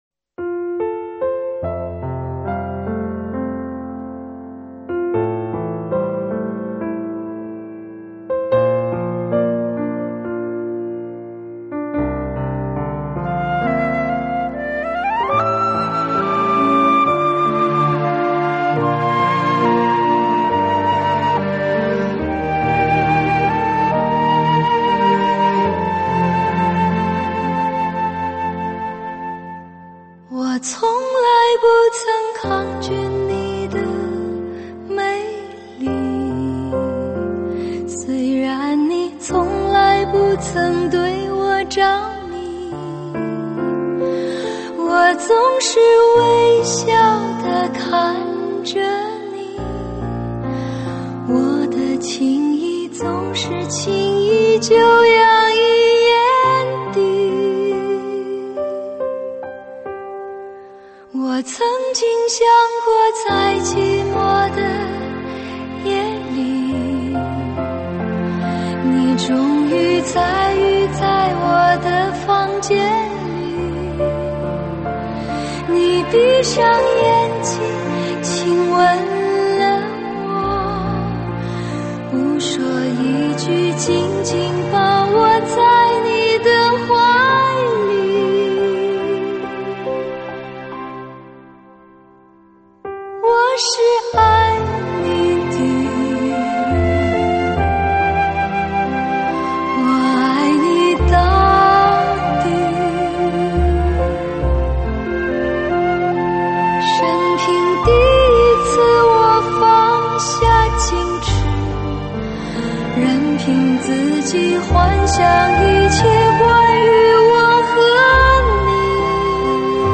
云一般的声音